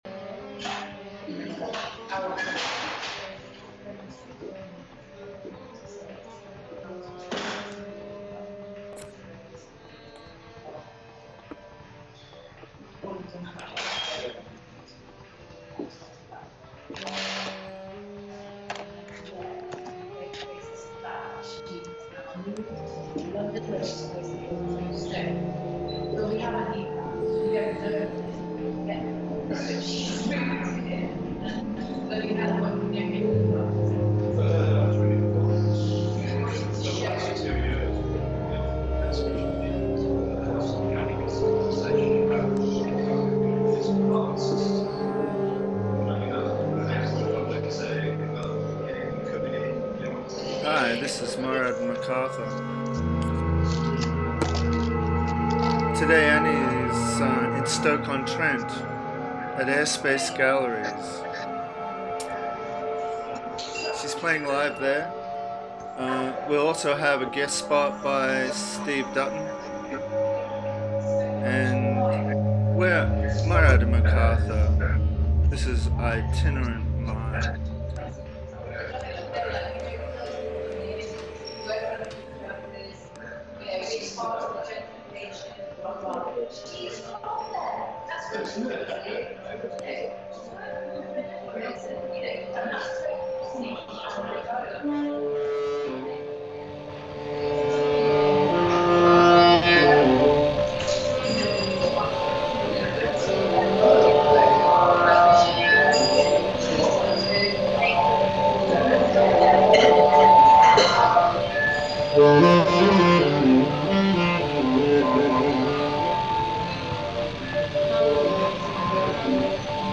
a completely improvised set of new material